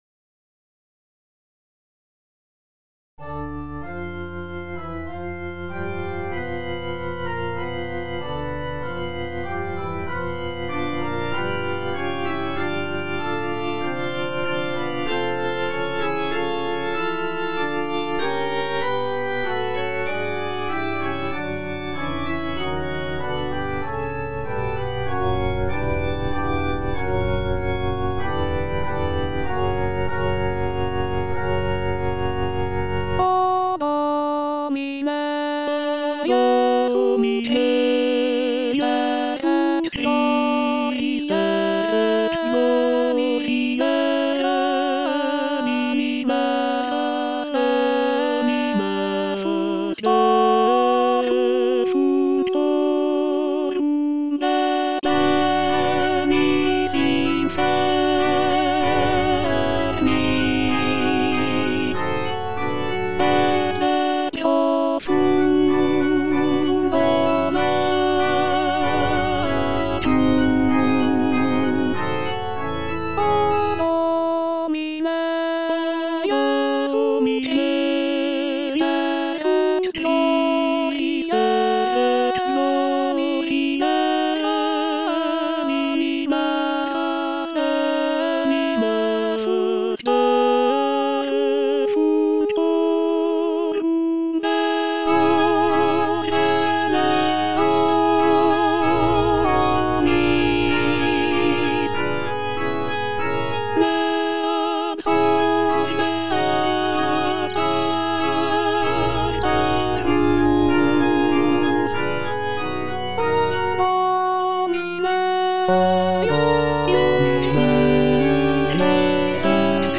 G. FAURÉ - REQUIEM OP.48 (VERSION WITH A SMALLER ORCHESTRA) Offertoire (All voices) Ads stop:
This version features a reduced orchestra with only a few instrumental sections, giving the work a more chamber-like quality. Fauré's Requiem is beloved for its gentle and comforting tone, with a focus on the beauty of the human voice and the peacefulness of the afterlife.